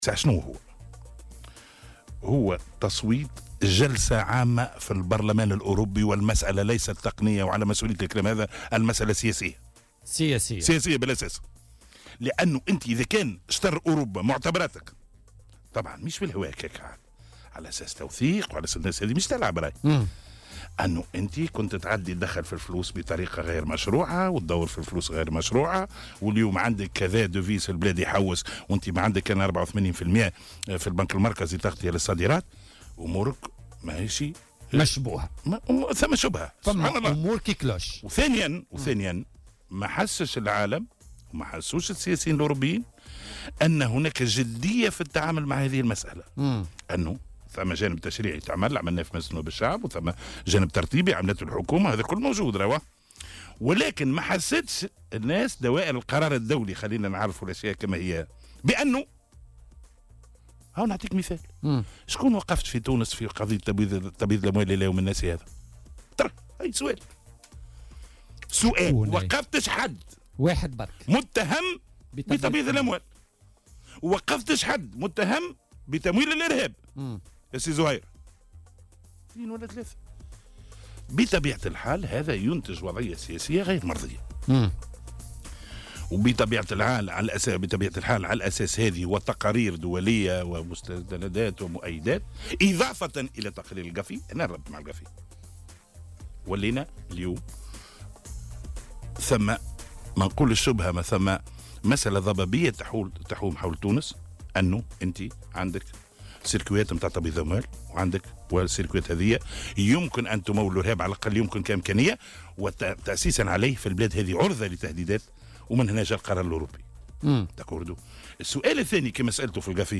وأضاف في مداخلة له اليوم في برنامج "بوليتيكا" أنه كان من المفروض التعامل بأكثر جدية مع المسألة، خاصة وأن مثل هذه التصنيفات سيكون لها انعكاسات وتداعيات خطيرة على تونس.